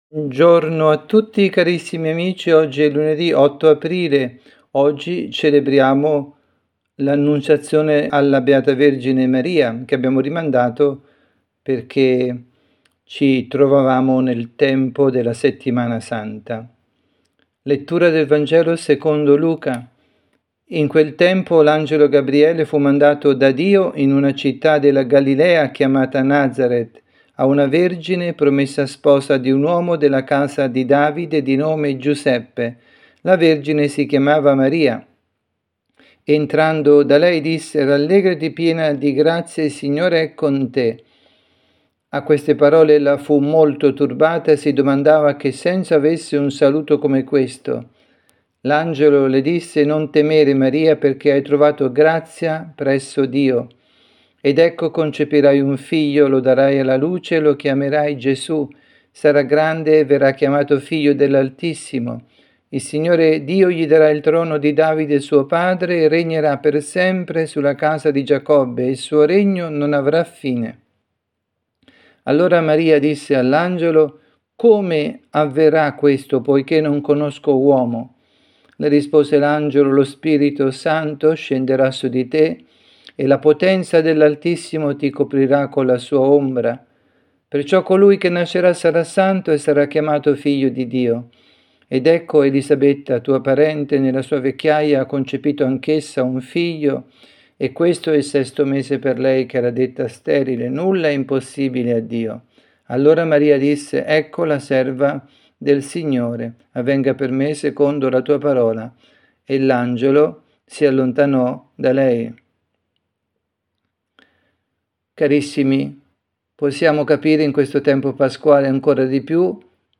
avvisi, Catechesi, Omelie, Pasqua
dalla Parrocchia Santa Rita – Milano